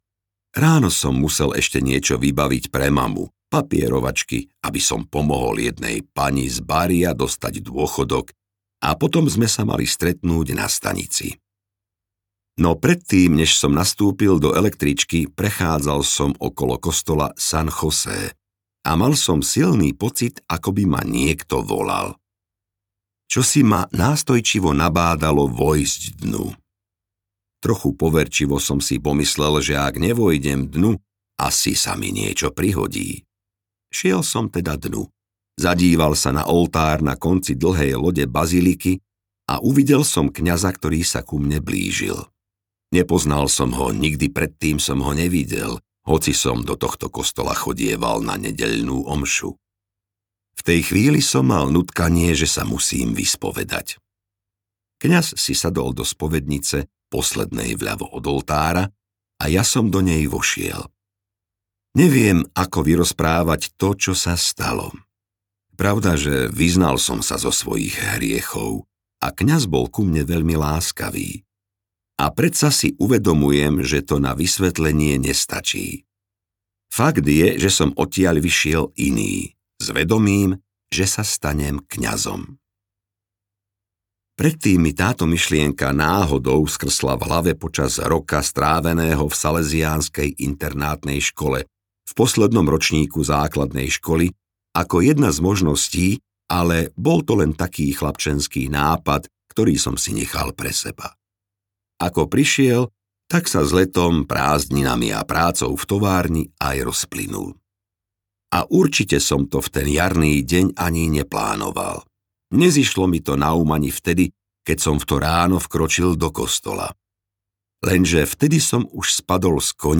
Nádej audiokniha
Ukázka z knihy